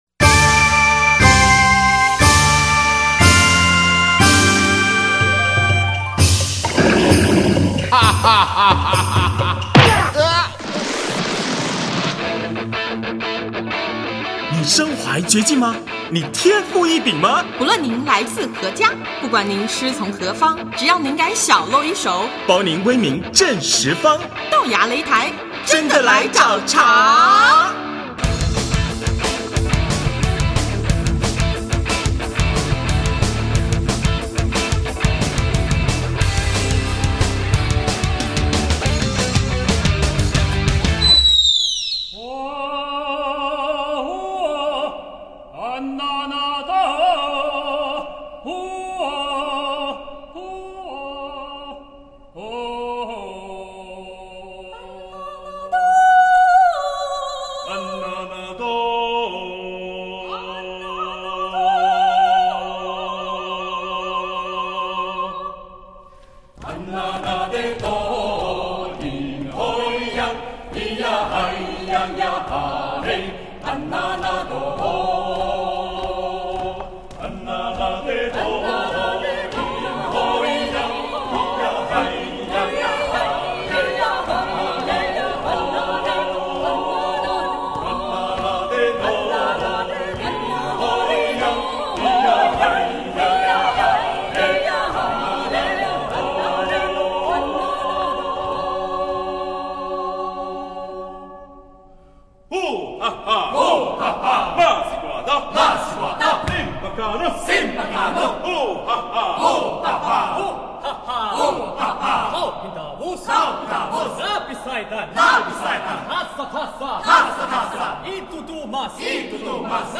诚挚邀请您参与2012年冬天的第一场圣诞音乐会，现场有「多元人声对话室内乐集(PVE)」以虔敬、优扬的乐音，为您传报爱与平安的喜讯。